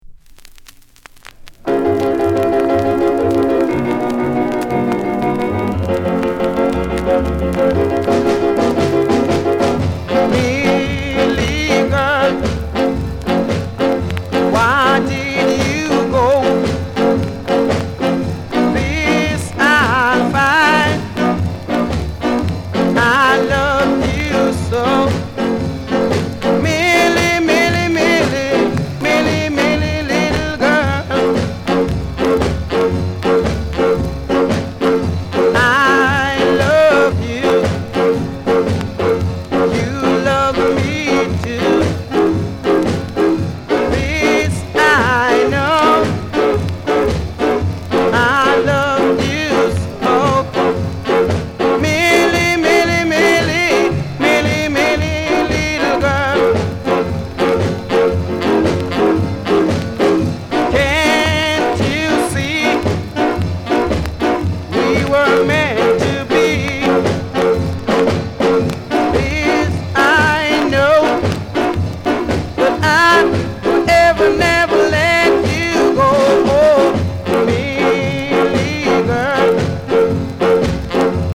Genre: Rhythm & Blues/Ska